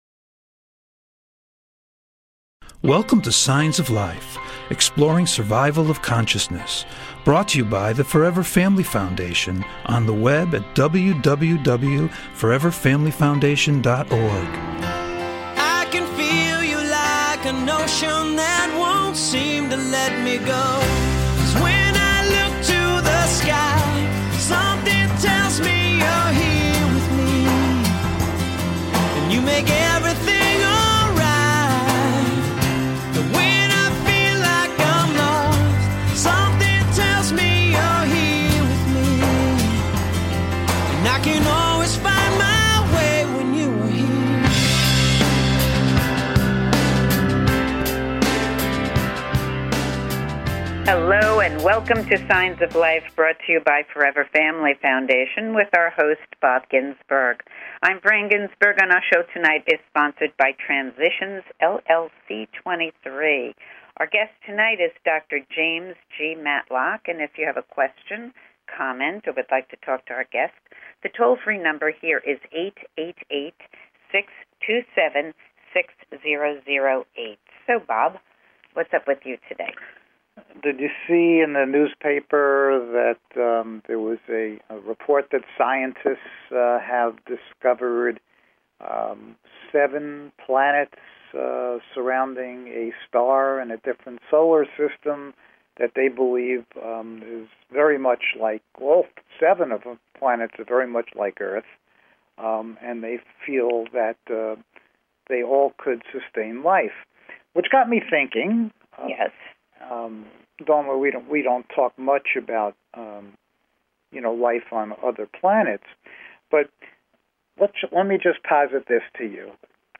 Signs of Life Radio Show is a unique radio show dedicated to the exploration of Life After Death!